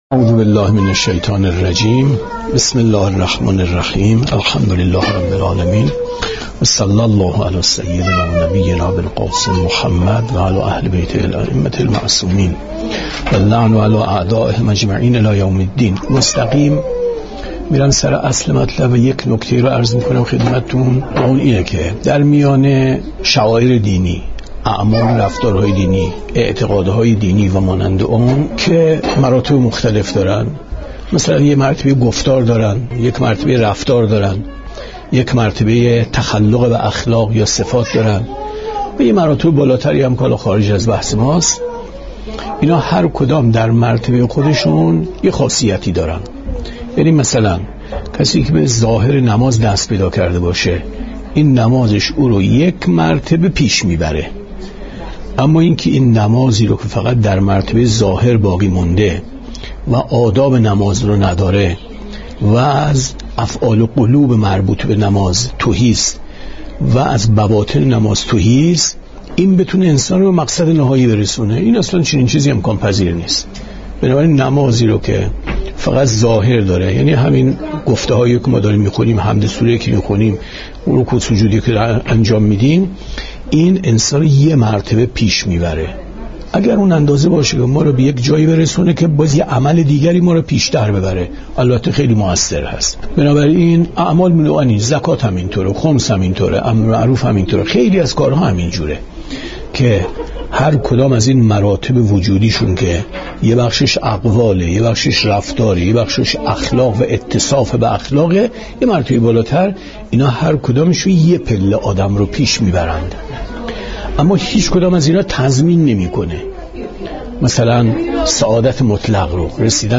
عید غدیر